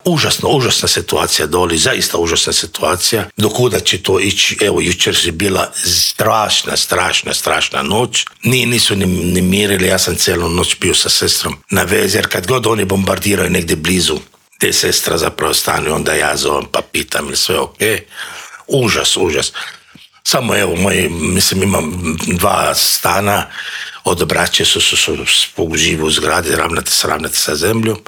U studiju Media servisa